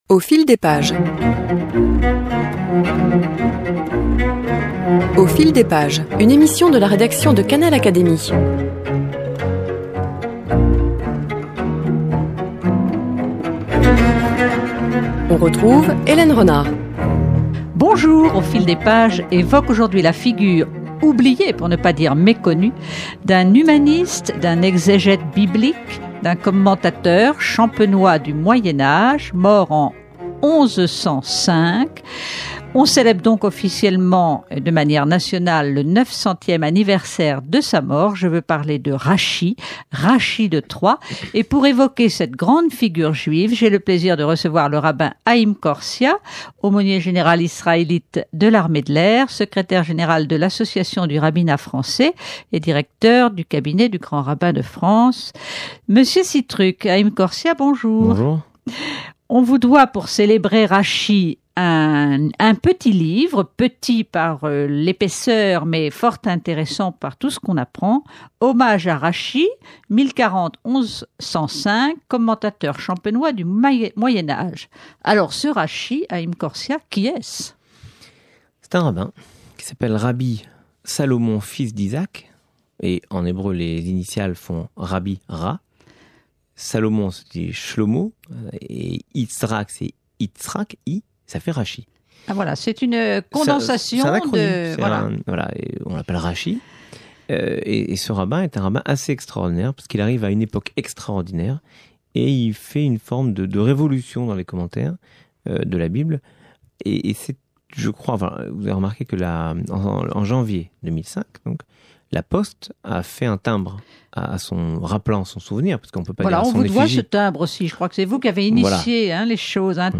2005 marque le 900ème anniversaire de la mort de Rachi, Rabbin, contemporain d’Urbain II (le Pape qui a prêché la première croisade). Hommage lui est rendu ici par Haïm Korsia, aumônier israélite de l’Armée de l’air française.